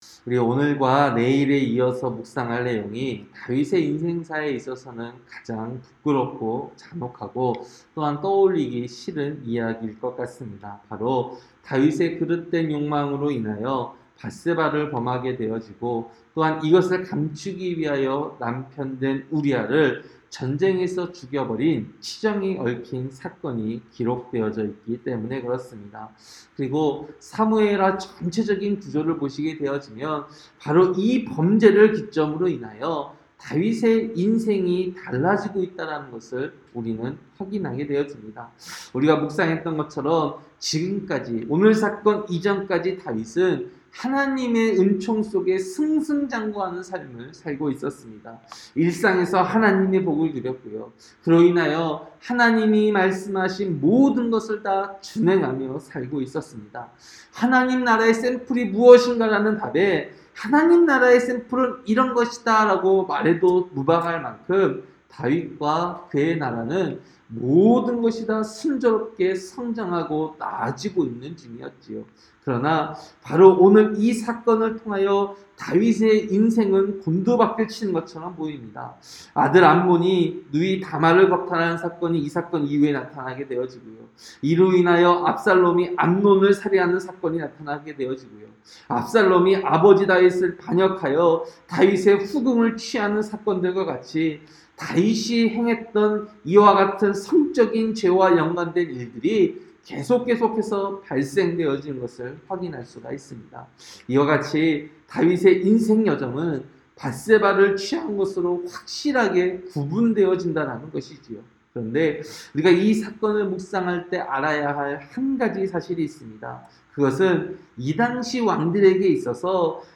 새벽설교-사무엘하 11장